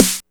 SNARE16.wav